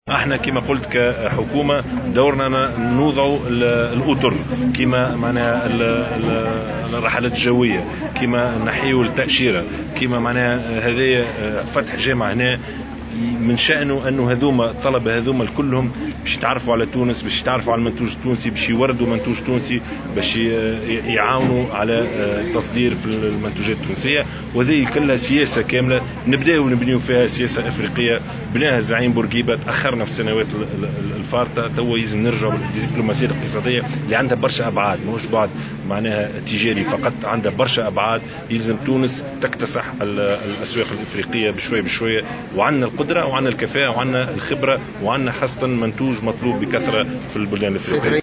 قال رئيس الحكومة يوسف الشاهد على هامش زيارته إلى العاصمة المالية باماكو، إن تونس قادرة على اكتساح الأسواق الإفريقية، ولها الكفاءة والخبرة والمنتوج الذي تبحث عنه هذه الأسواق بكثرة.